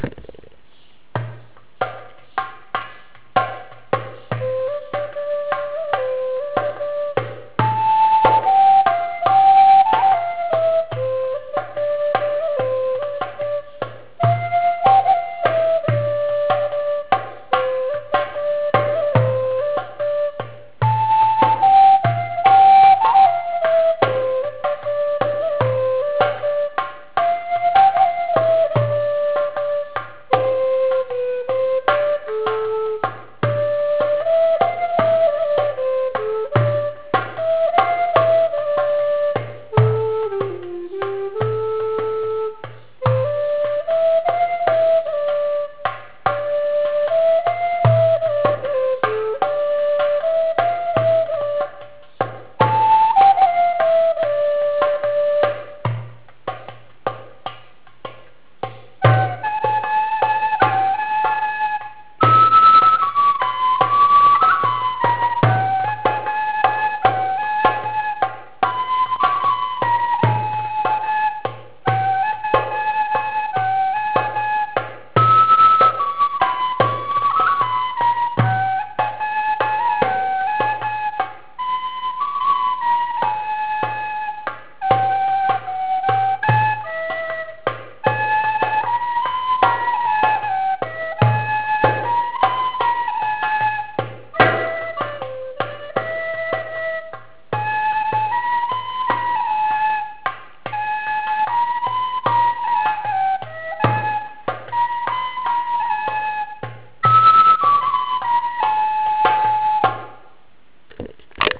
הקלטתי בMP3 ולכן זה לא באיכות.
החליליות:אני
בדרבוקה:חברה שלי
לחלילית סופרן.
שומעים מעט בעיות בתיאום בין הכלים.
יש מעבר חד כשעברת בין החליליות... וזה צורם.